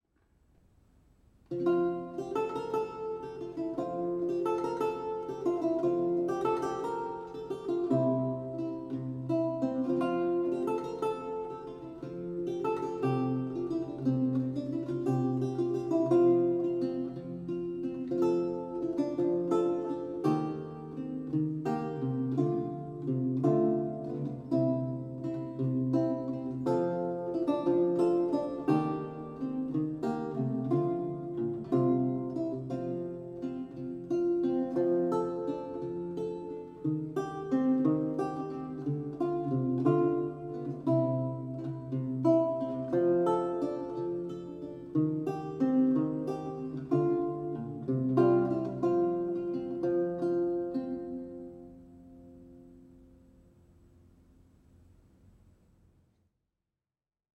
Audio recording of a lute piece from the E-LAUTE project
a 16th century lute music piece originally notated in lute tablature